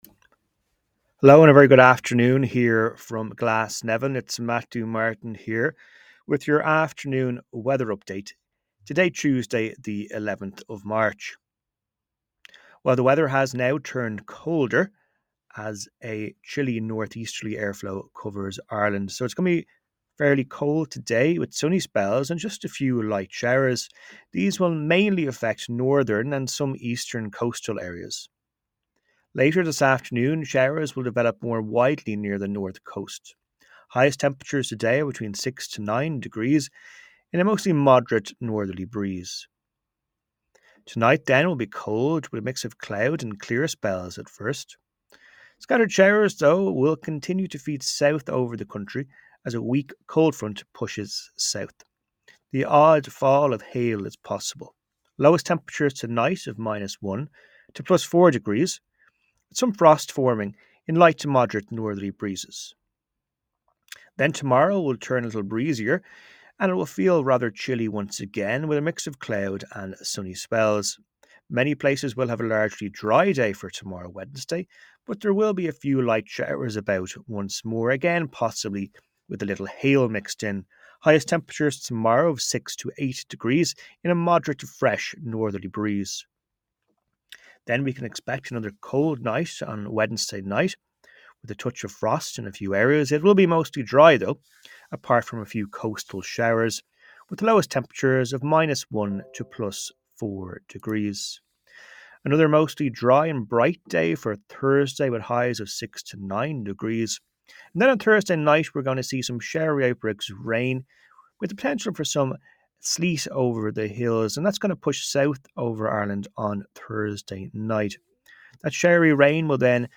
Weather Forecast from Met Éireann / Ireland's Weather 12pm Tuesday 11 March 2025